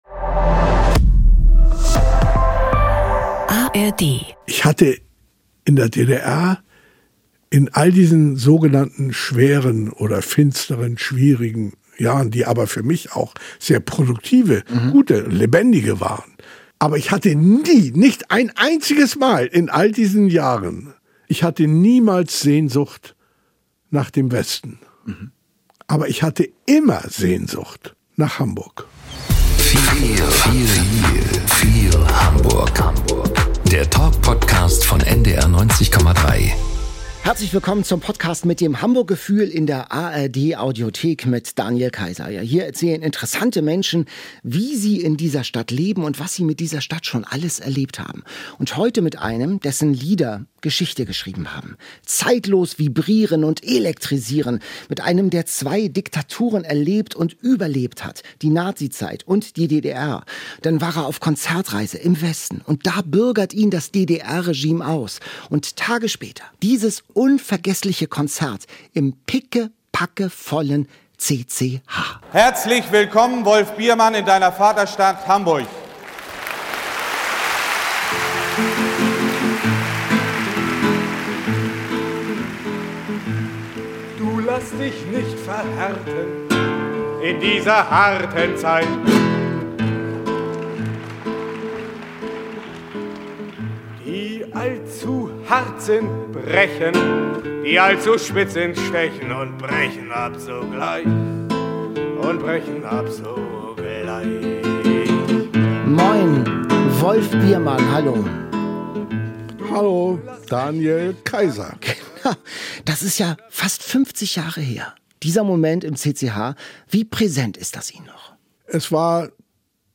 spricht mit Persönlichkeiten aus der Stadt - mit Menschen, die eine Geschichte zu erzählen haben.